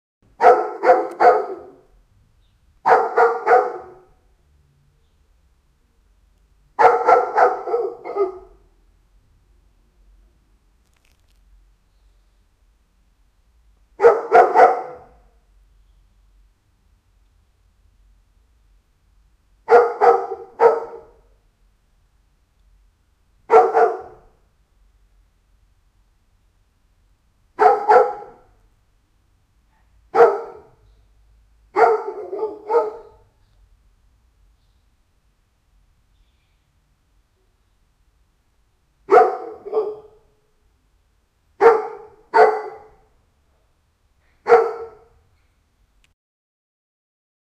a_dog_barking.mp3